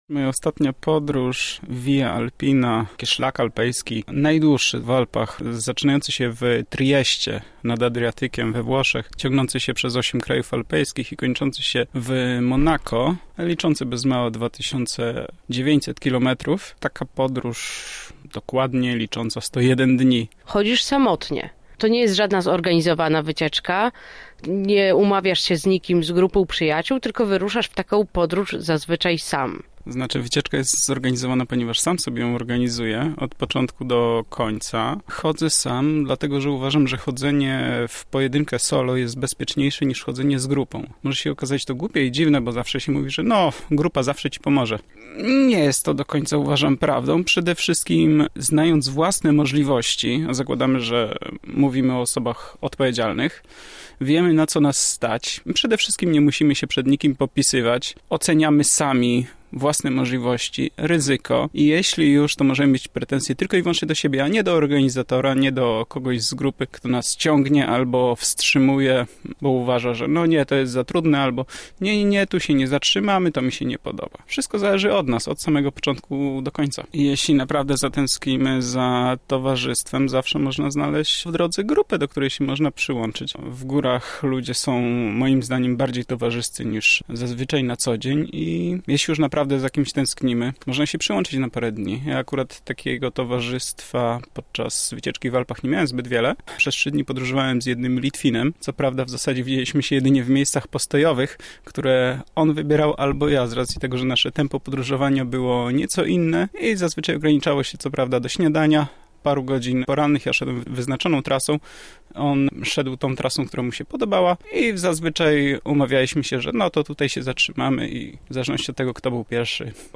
rozmawiała nasza reporterka